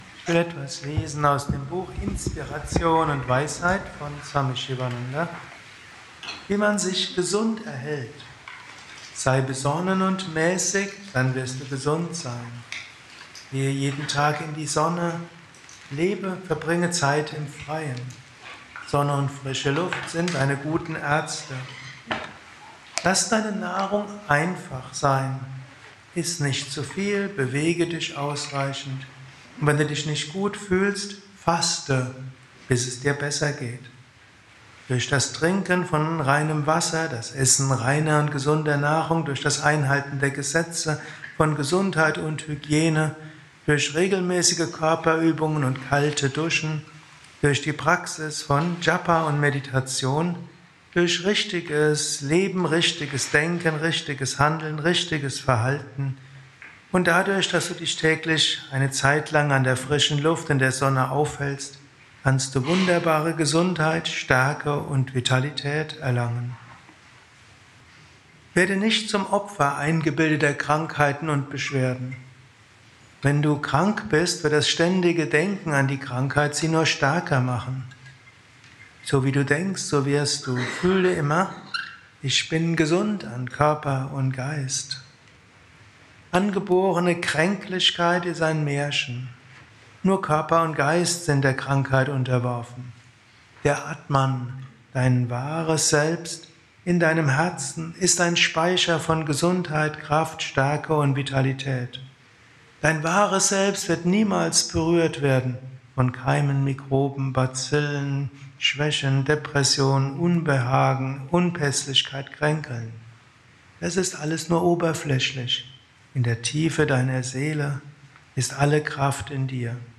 Dies ist ein kurzer Vortrag als Inspiration für den heutigen Tag
während eines Satsangs gehalten nach einer Meditation im Yoga